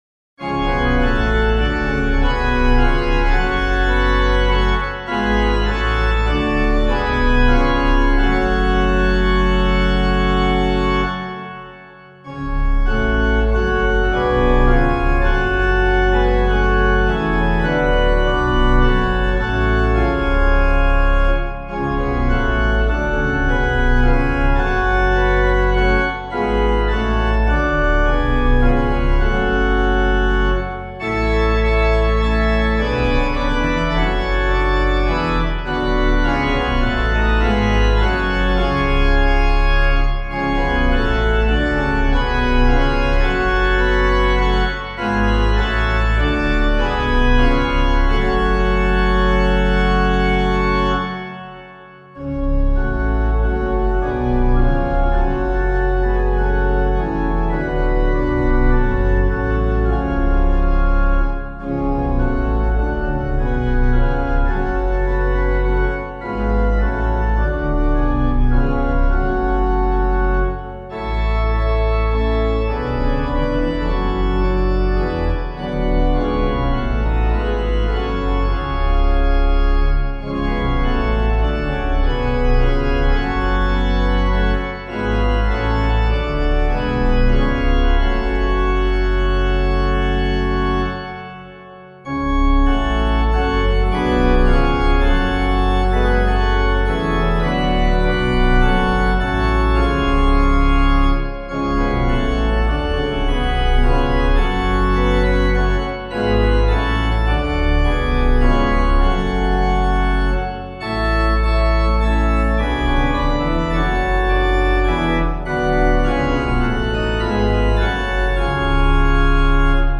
Composer:    Welsh folk melody, Llwybrau Moliant, 1872,
organ